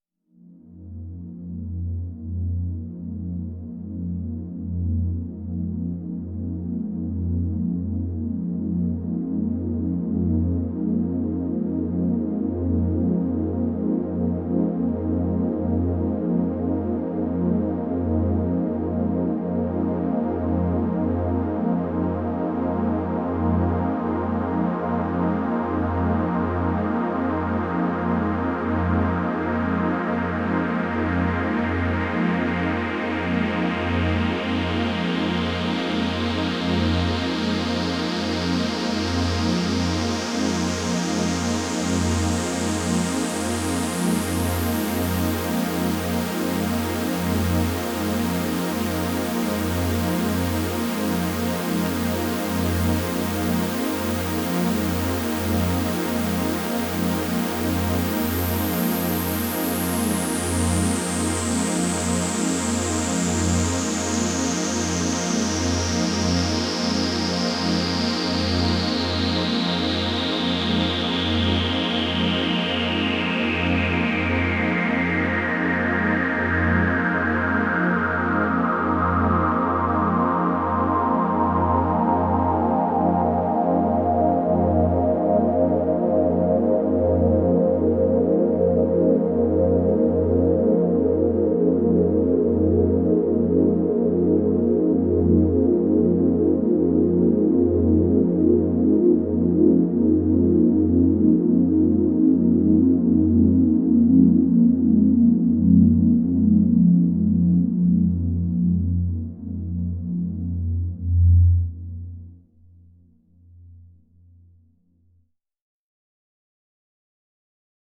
Так, вот еще ob-6 с найденой А# и чутка синтовского хоруса.